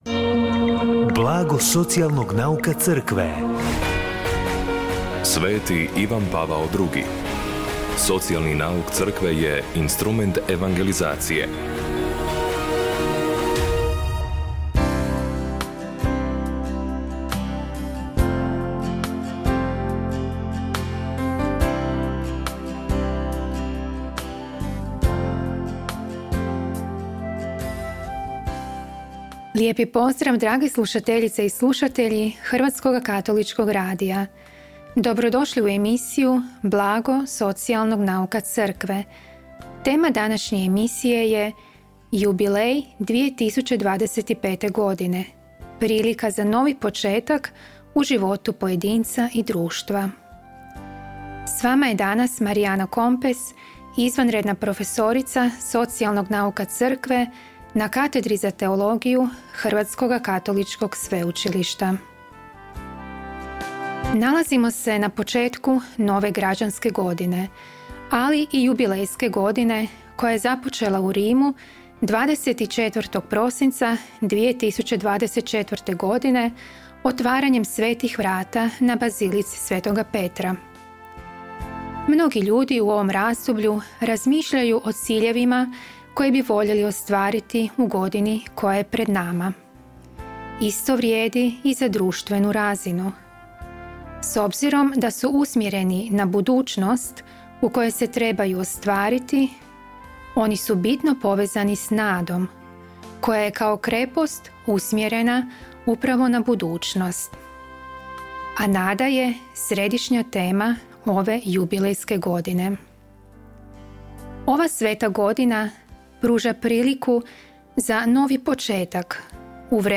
Emisiju na valovima HKR-a “Blago socijalnog nauka Crkve” subotom u 16:30 emitiramo u suradnji s Centrom za promicanje socijalnog nauka Crkve Hrvatske biskupske konferencije.